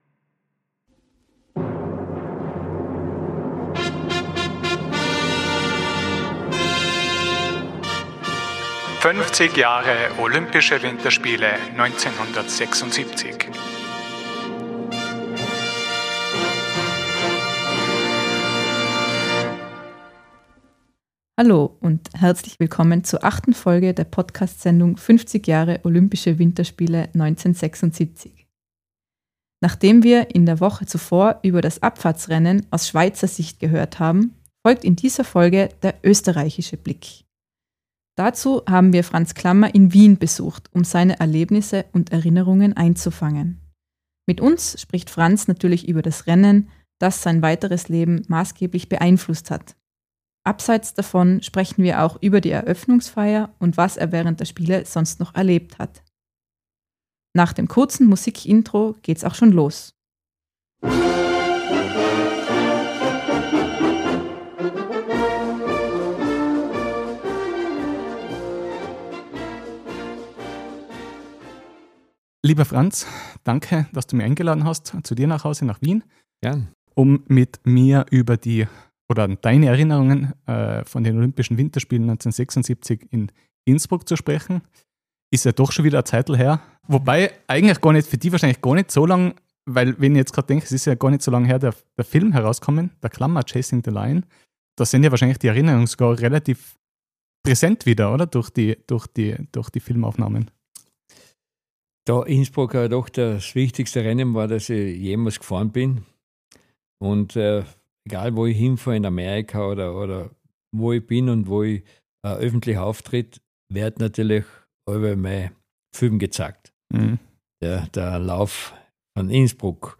Dazu haben wir Franz Klammer in Wien besucht, um seine Erlebnisse und Erinnerungen einzufangen. Mit uns spricht Franz natürlich über das Rennen, das sein weiteres Leben maßgeblich beeinflusst hat. Abseits davon sprechen wir auch über die Eröffnungsfeier und was er während der Spiele sonst noch erlebt hat.